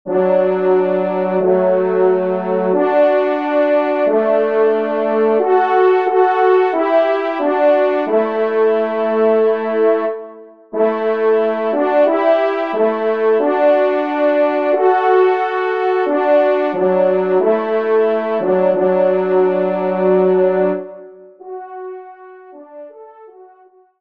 Genre : Musique Religieuse pour Trois Trompes ou Cors
Pupitre 2°Trompe